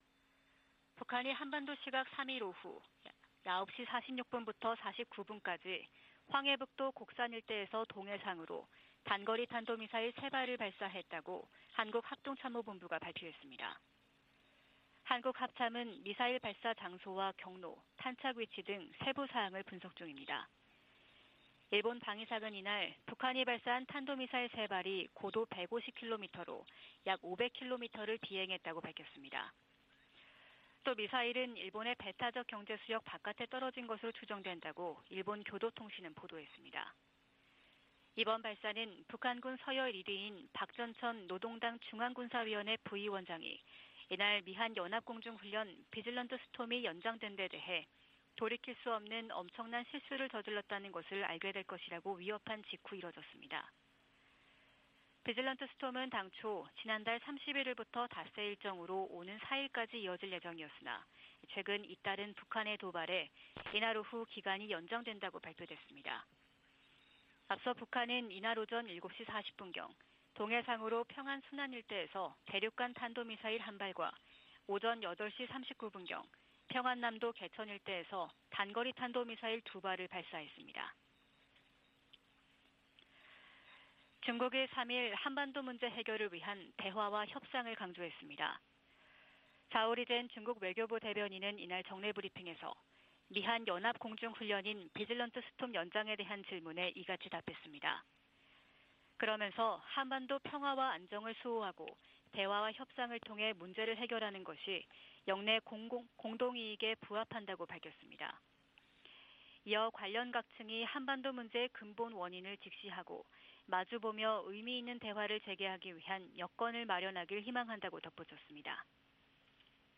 VOA 한국어 '출발 뉴스 쇼', 2022년 11월 4일 방송입니다. 북한 김정은 정권이 어제 동해와 서해상에 미사일과 포탄을 무더기로 발사한 데 이어 오늘은 대륙간탄도미사일, ICBM을 쏘면서 도발 수위를 높였습니다.